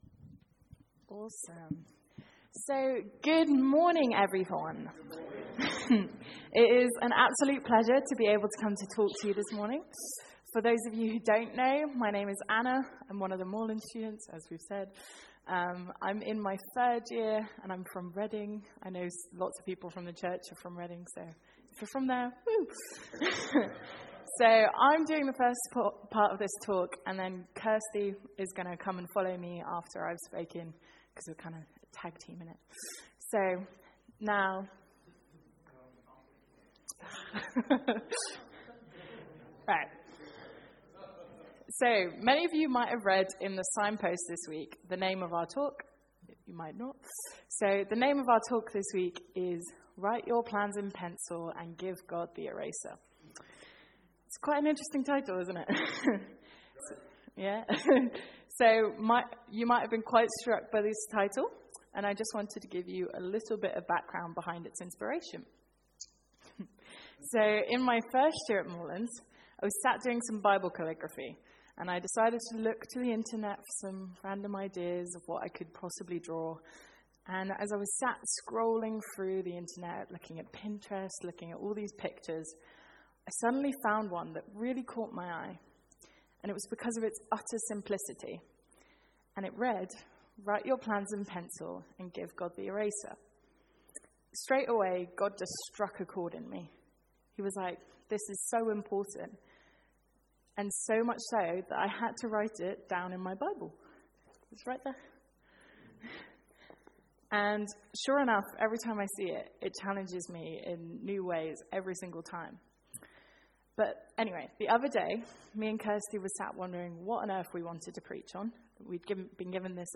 Sermon Archives